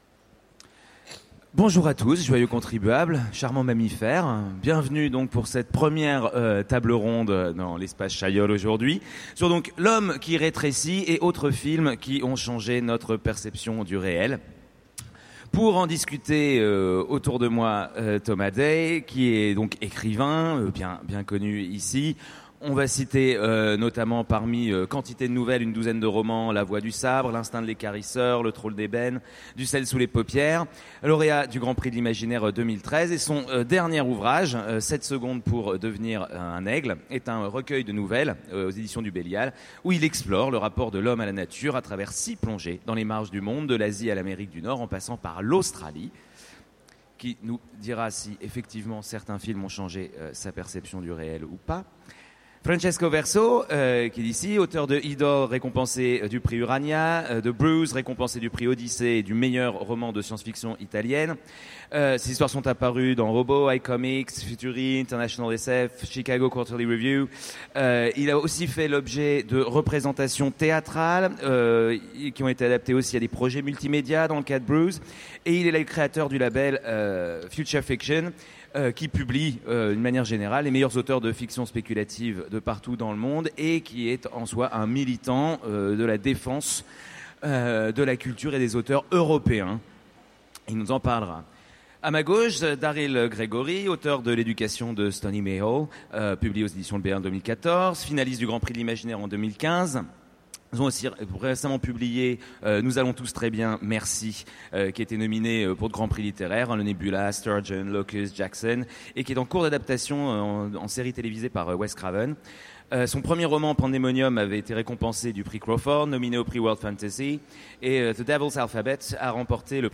Utopiales 2015 : Conférence L’homme qui rétrécit et autres films qui ont changé notre perception du réel